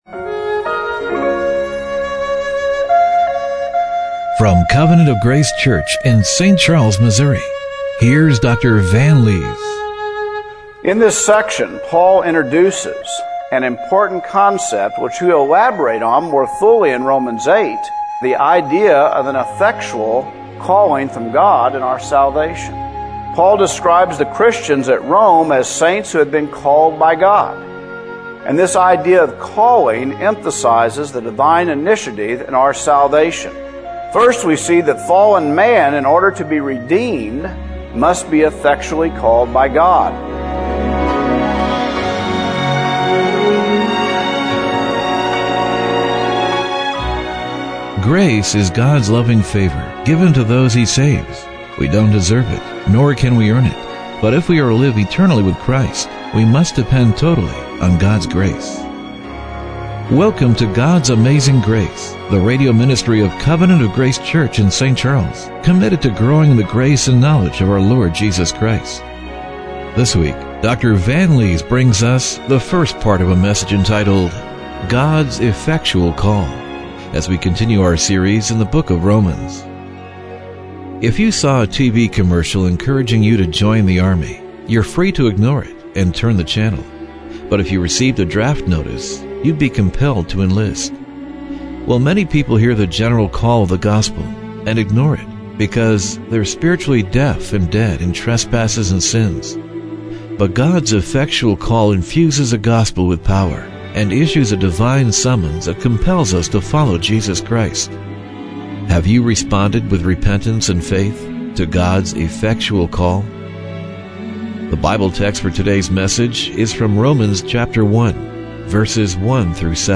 Romans 1:1-7 Service Type: Radio Broadcast Have you responded with repentance and faith to God's effectual Call?